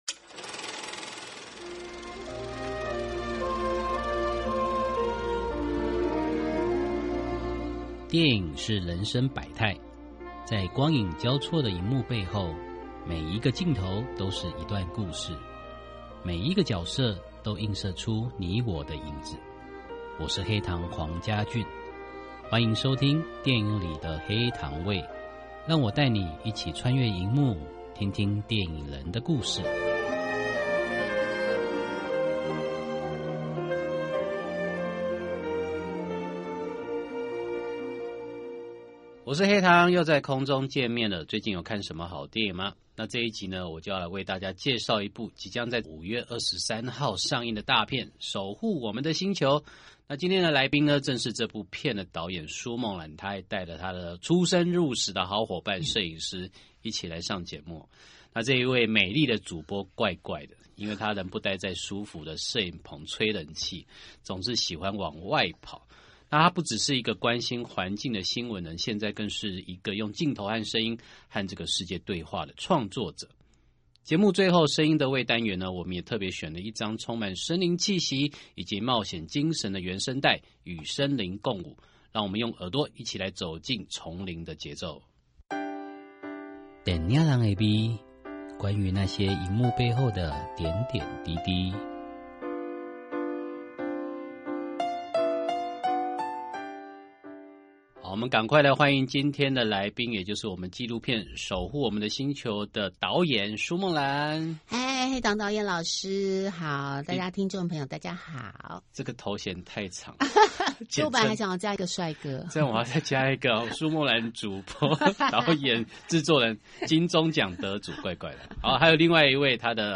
訪問大綱 1.你曾說過「字典裡沒有不可能」，許多人嚮往攝影棚內的光鮮亮麗，而你總是選擇往艱困的環境走，當初是什麼樣的信念讓你願意踏上南北極、沙漠與深海這些極限地景拍攝？